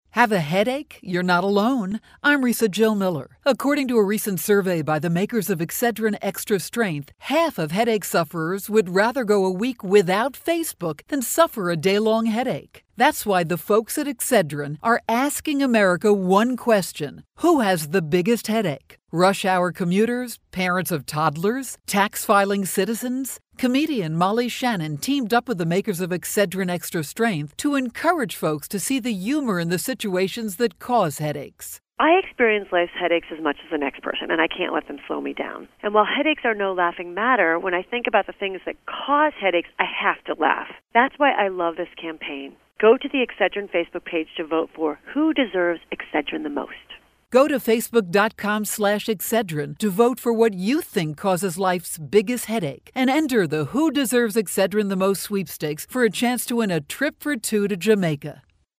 April 22, 2013Posted in: Audio News Release